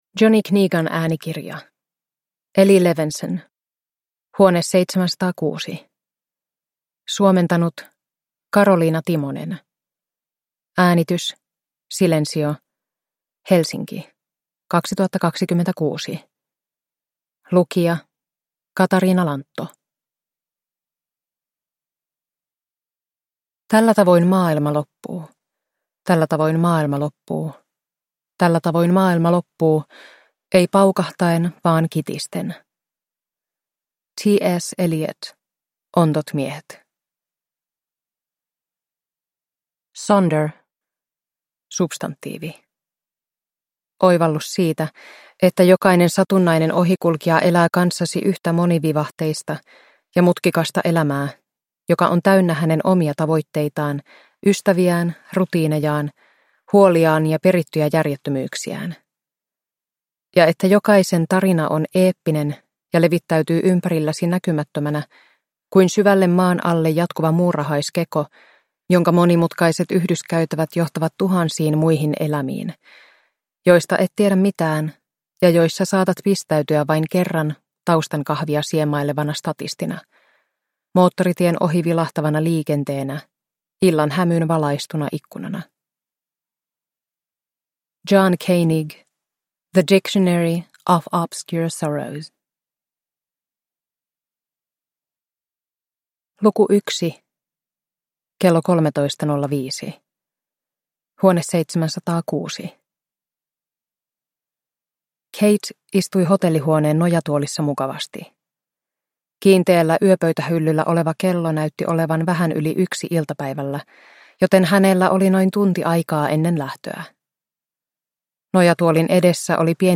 Huone 706 – Ljudbok